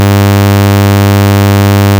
つうわけで、ツールに「sawtooth_r」を追加で実装してみる。
こんな音になった……んが、聴感上では違いがわからないな。